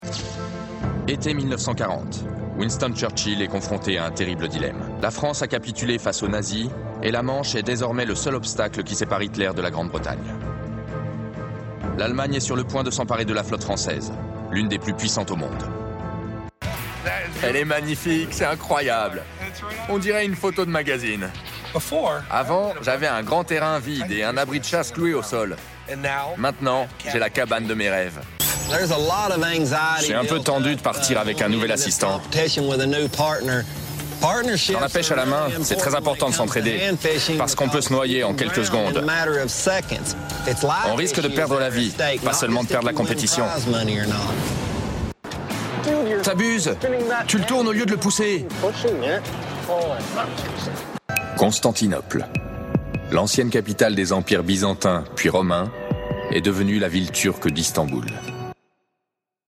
Voix off
Démo Narration / Voice-Over